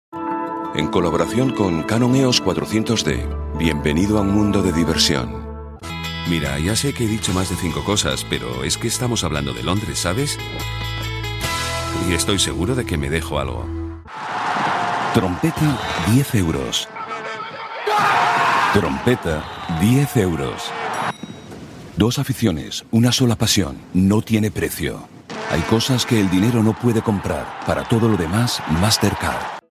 Commercial 1